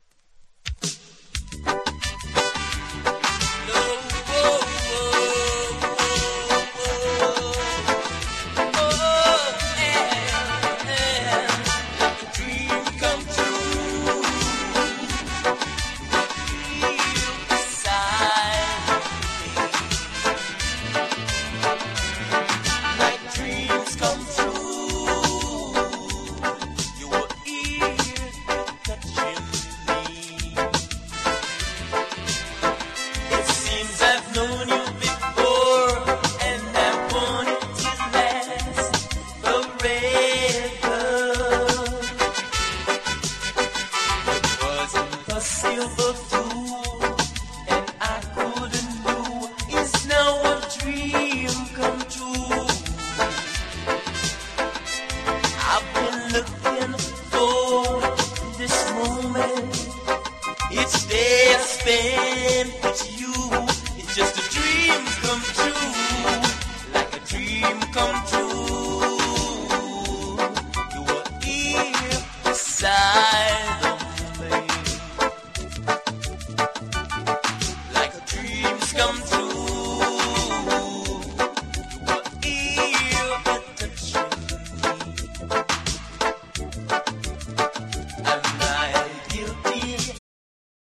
REGGAE / SKA / DUB